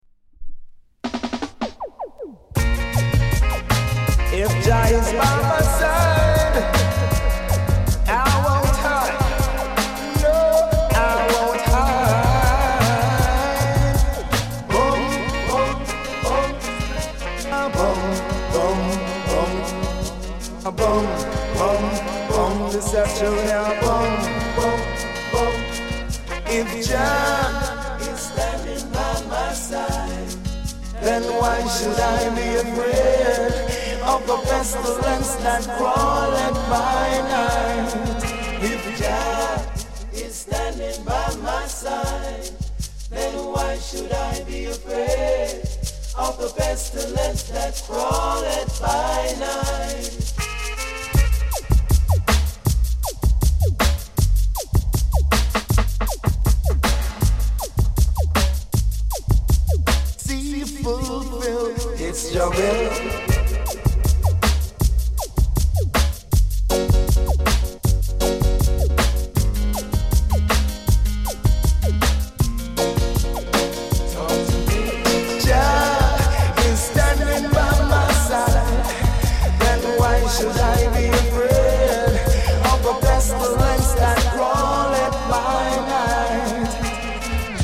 category Reggae